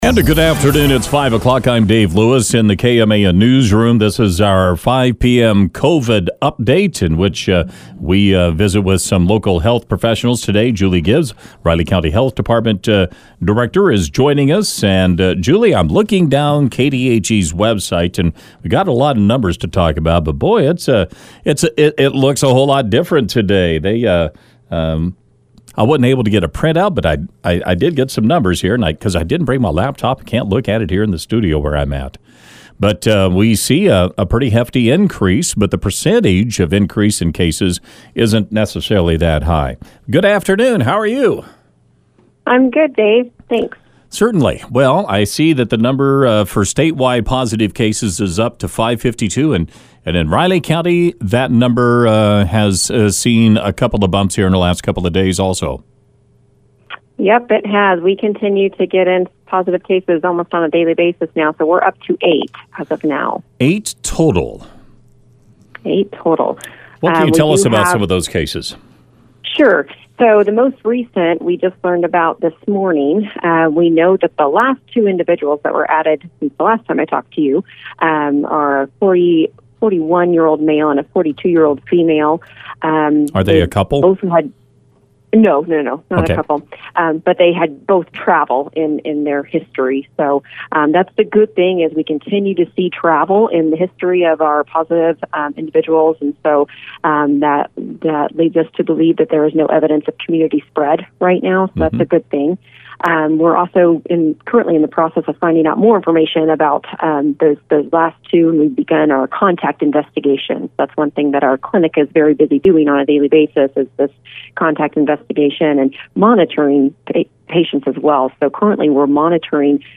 RCHD Director Julie Gibbs joined News Radio KMAN during the 5 p.m. hour for our daily COVID-19 update.